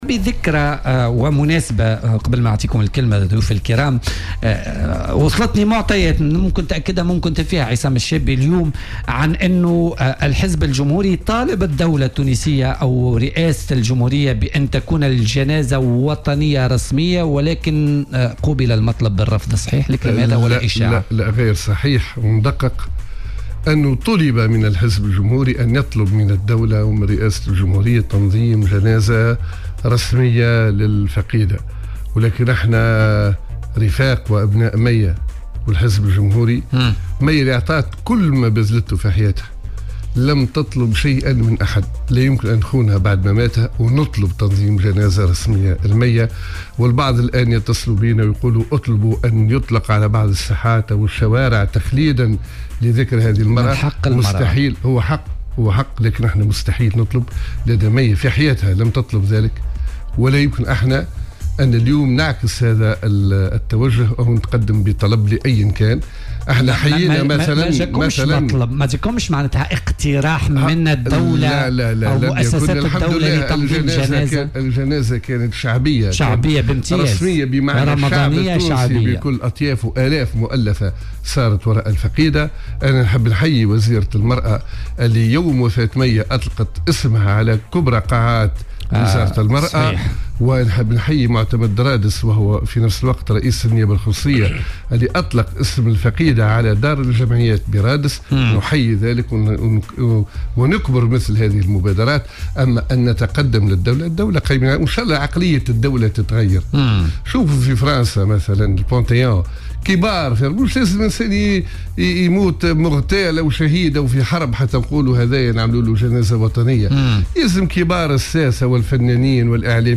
وأوضح ضيف "بوليتيكا" على "الجوهرة اف أم" أن الحزب تلقى اقتراحا للمطالبة بتنظيم مثل هذه الجنازة لكن قيادات الحزب رفضت وفضلت أن تكون جنازتها شعبية خاصة.